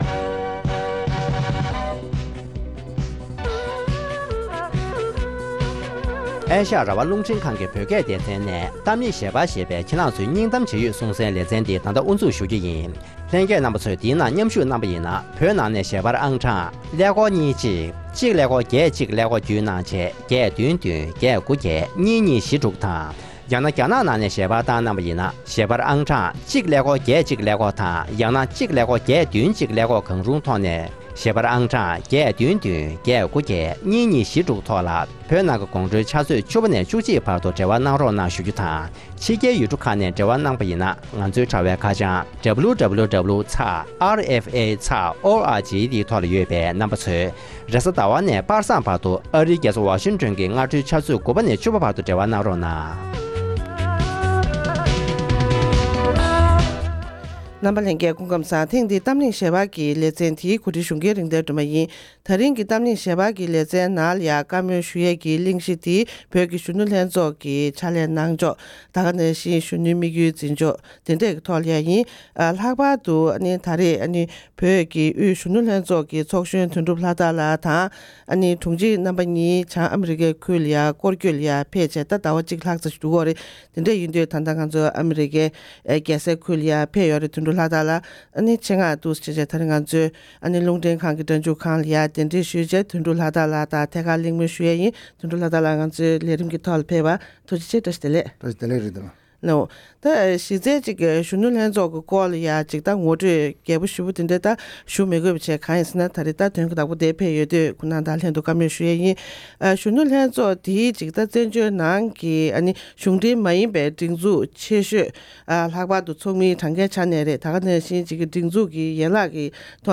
༄༅། །དེ་རིང་གི་གཏམ་གླེང་ཞལ་པར་གྱི་ལེ་ཚན་ནང་།